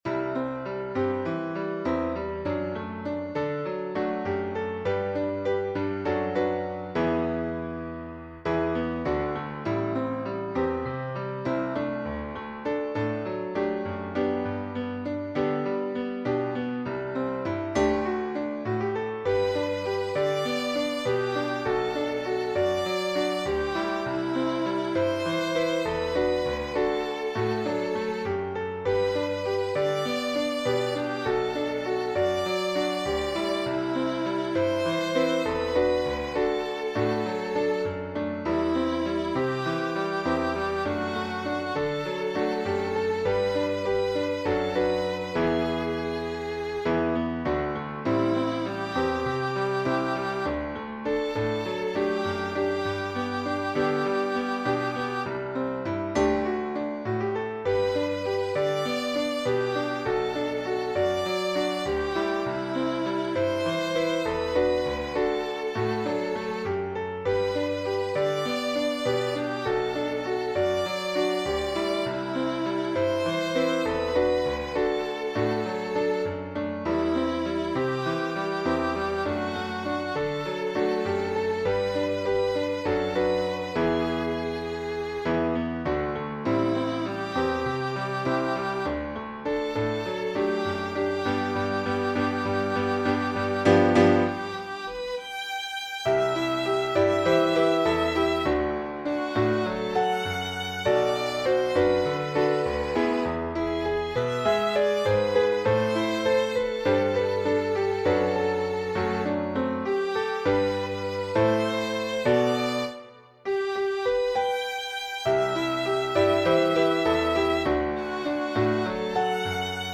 Hymn Composer
The song is structured as a call and response.
Accompaniment